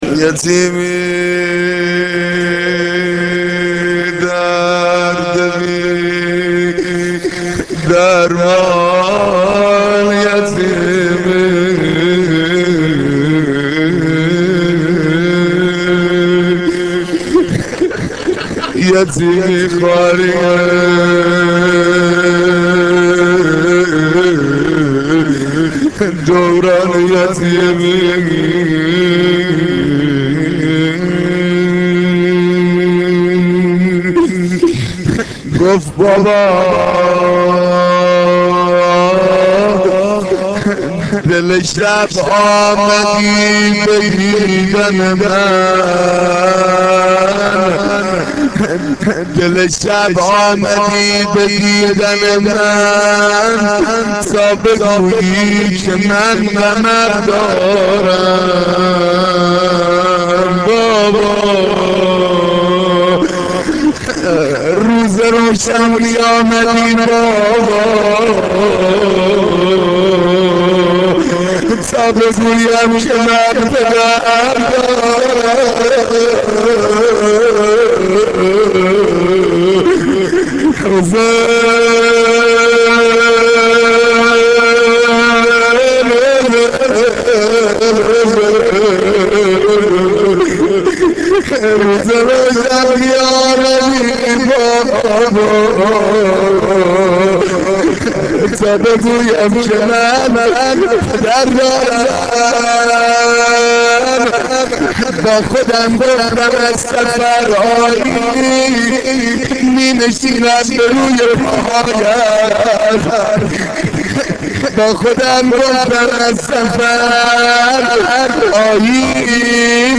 مداحی روضه شب سوم